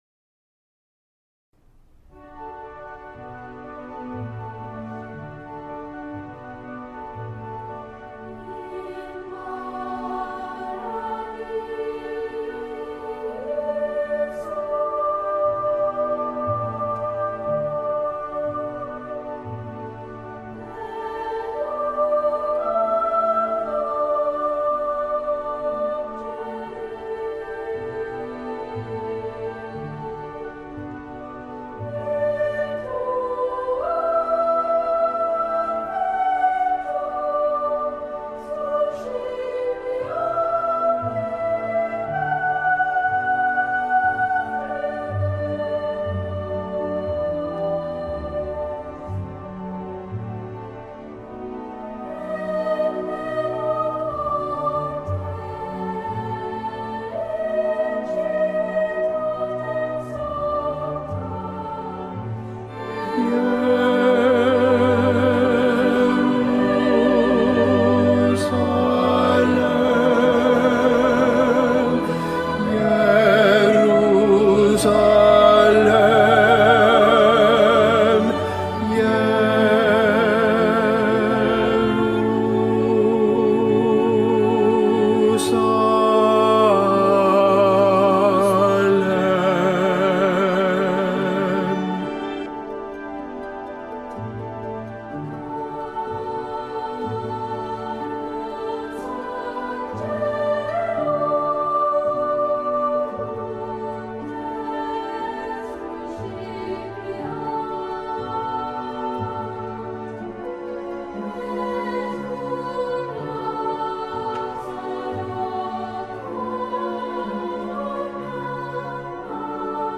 Tenor II
Mp3 Profesor